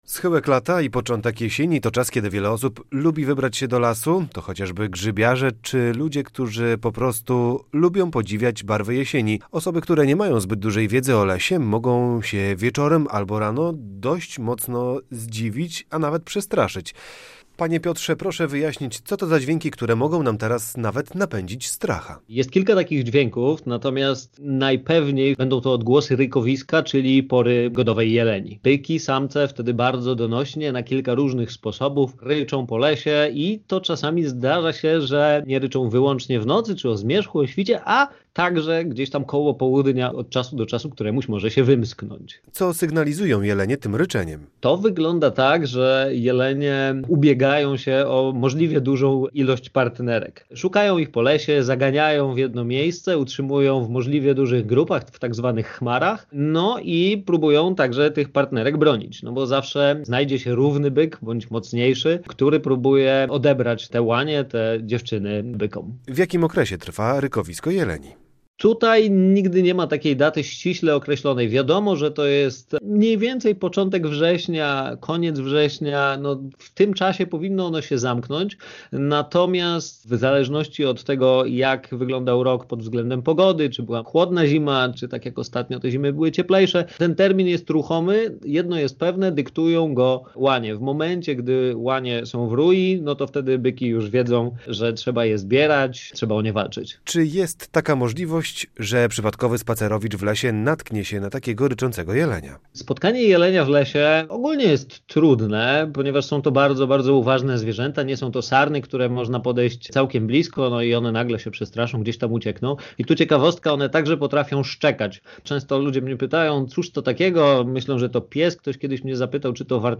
podróżnik i youtuber